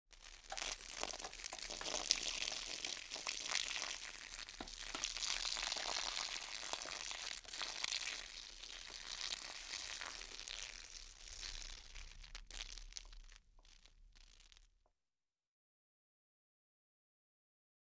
Water, Dripping | Sneak On The Lot
Light Stream Of Water Being Poured Onto Dirt